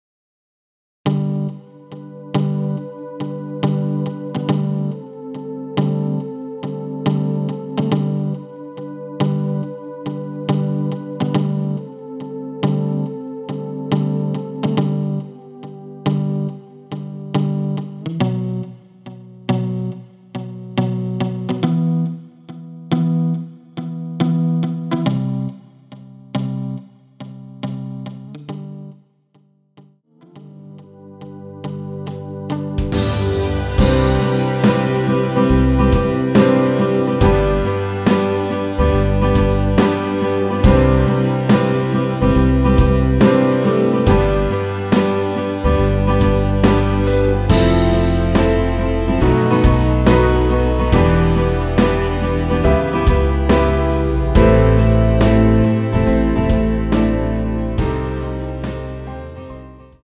여성분이 부르실수 있는 키로 제작 하였습니다.(미리듣기 참조)
앨범 | O.S.T
앞부분30초, 뒷부분30초씩 편집해서 올려 드리고 있습니다.